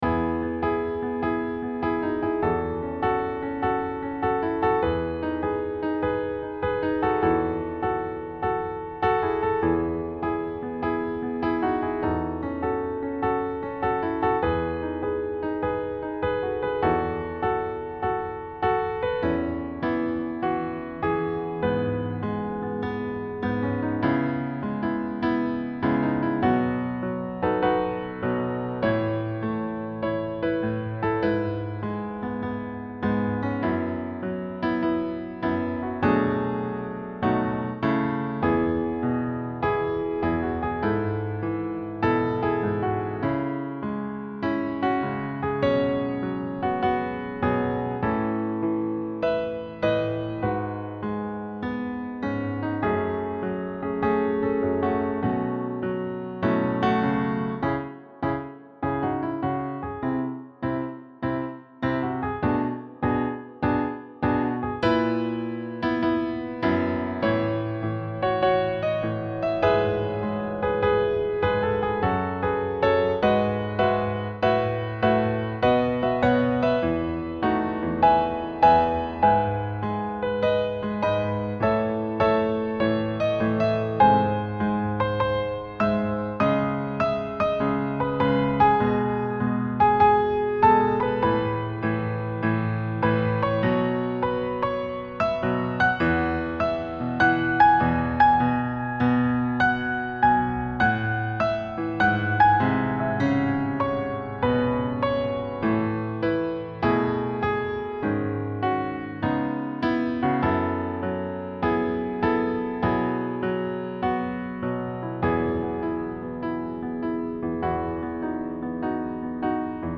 未分類 ピアノ 懐かしい 穏やか 音楽日記 よかったらシェアしてね！